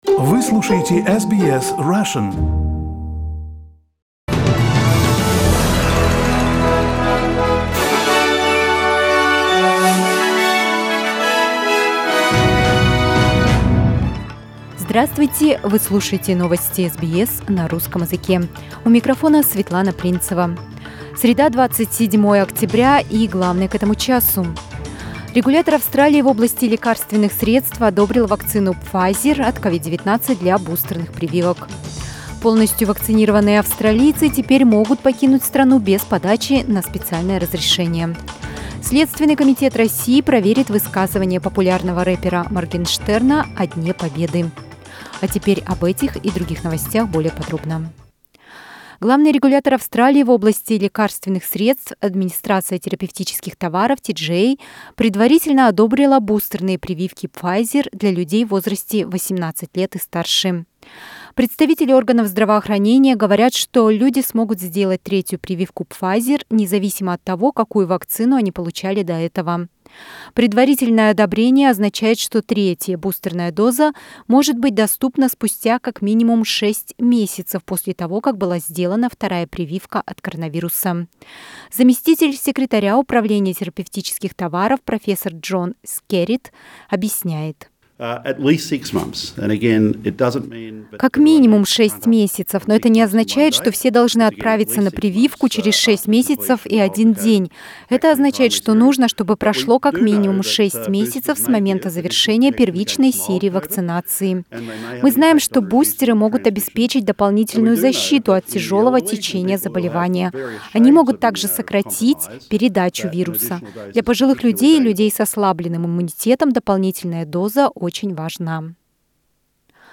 Новости SBS на русском языке - 27.10